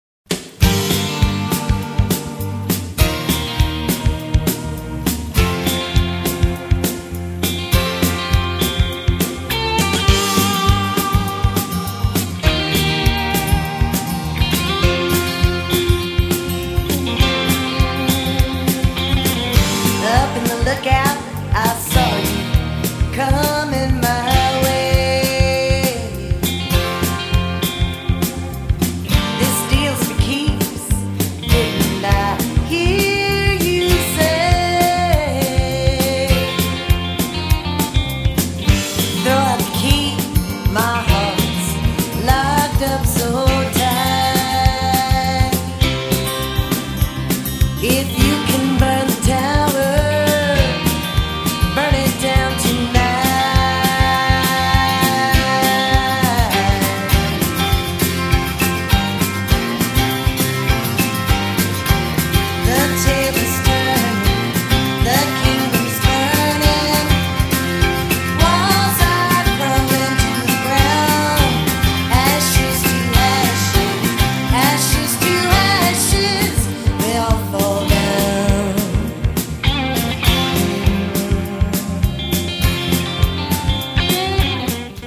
recorded in Austin and Houston,TX